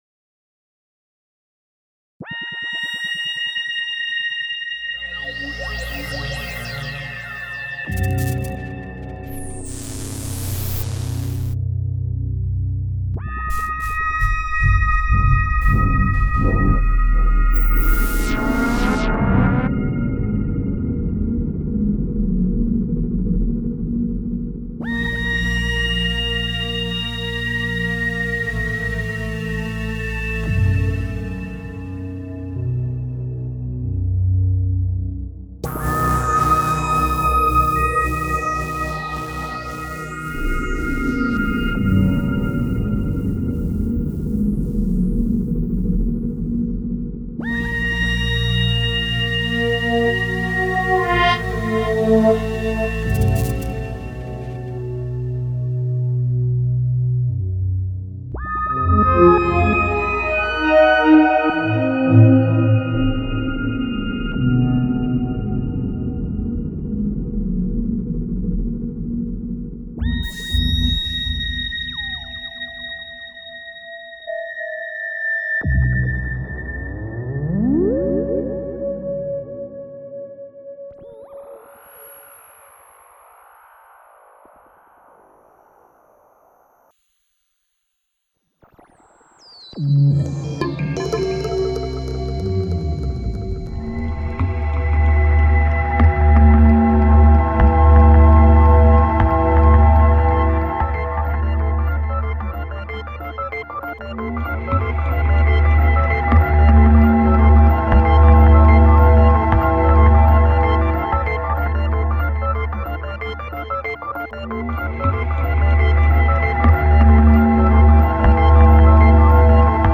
he focused on creating an ambient music
with the use of only synthetizers.